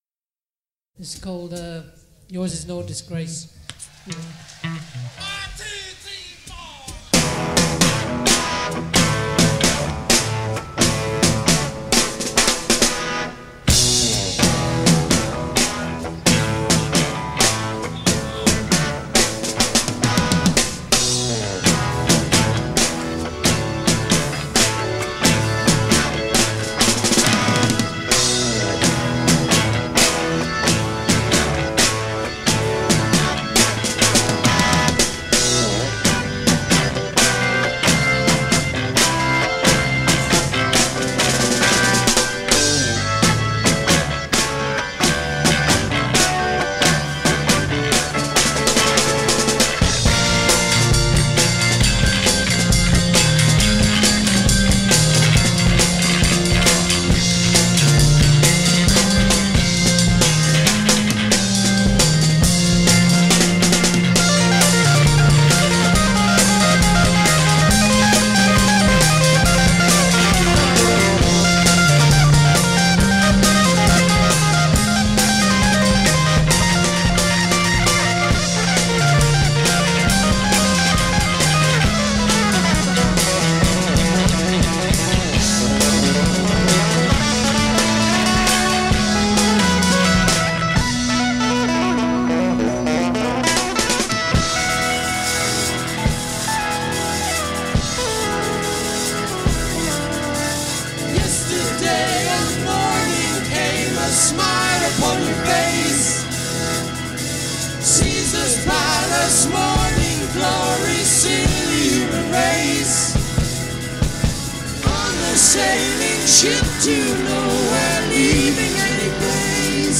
Prog